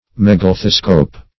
Search Result for " megalethoscope" : The Collaborative International Dictionary of English v.0.48: Megalethoscope \Meg`a*leth"o*scope\, n. [Mega- + alethoscope.] An optical apparatus in which pictures are viewed through a large lens with stereoptical effects.
megalethoscope.mp3